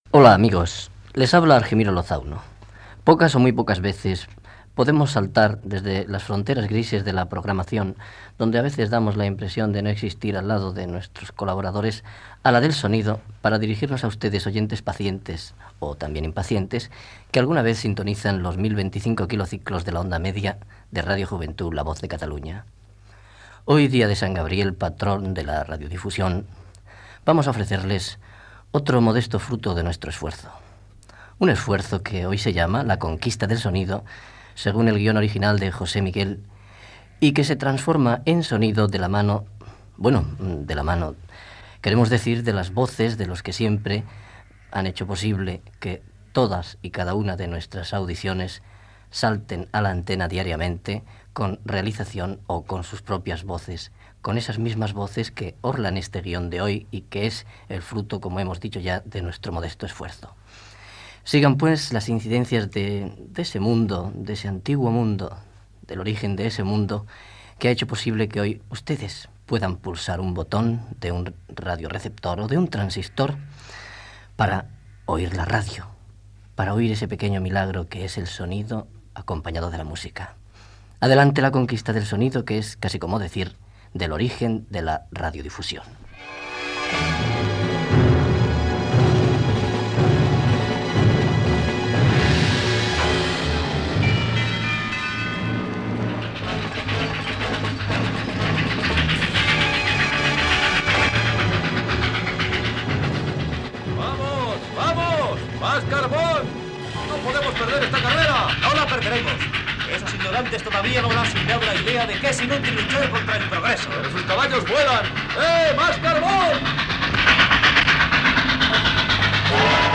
Emissió del dia del patró de la ràdio Sant Gabriel. Presentació, ficció sonora en la qual es fa una cursa entre un tren de vapor i uns cavalls galopant. Els pioners del so, de la ràdio i del cinema sonor